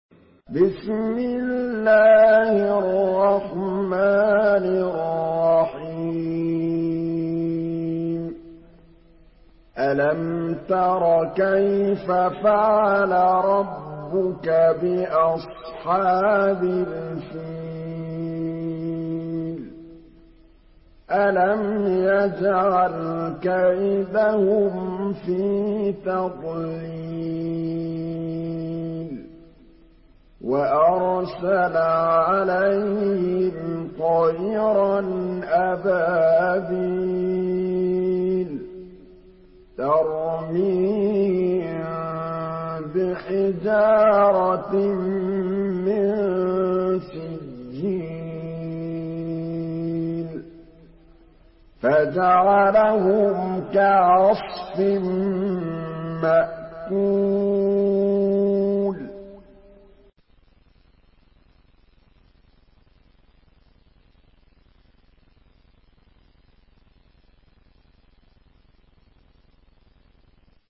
Surah Fil MP3 by Muhammad Mahmood Al Tablawi in Hafs An Asim narration.
Murattal